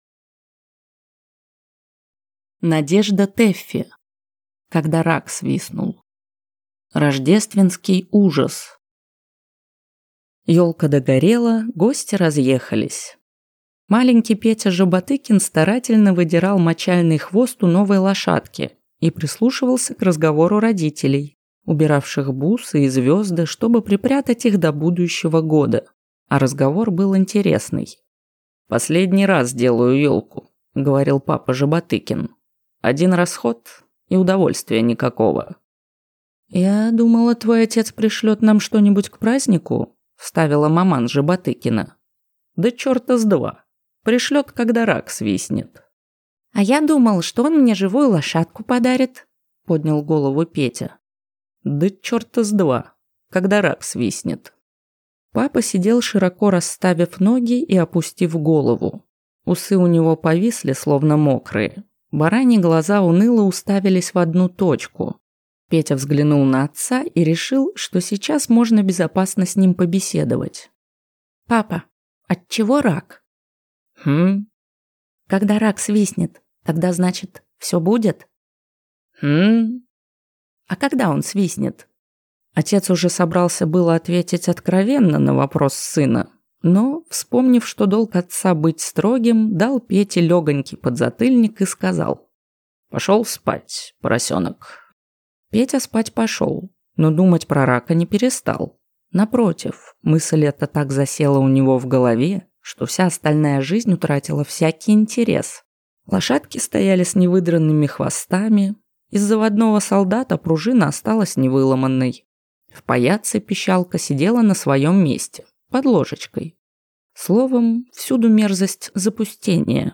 Аудиокнига Когда рак свистнул | Библиотека аудиокниг